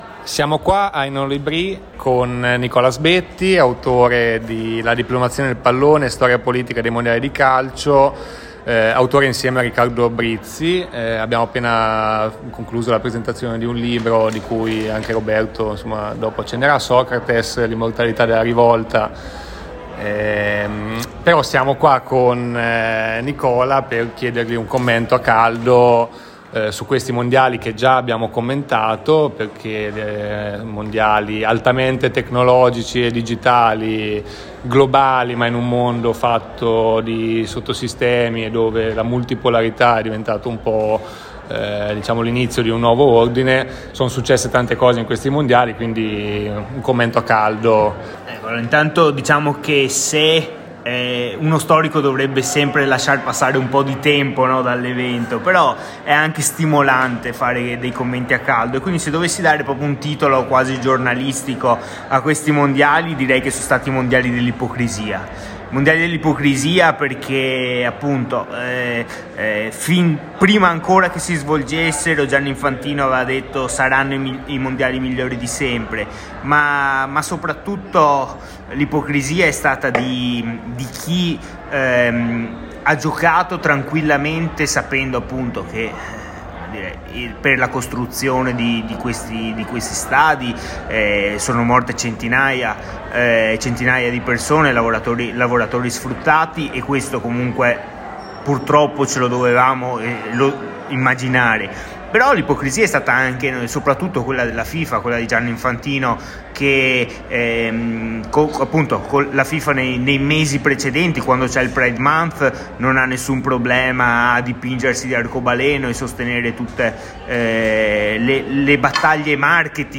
Mondiali multipolari e digitali #2: i mondiali dell'ipocrisia - intervista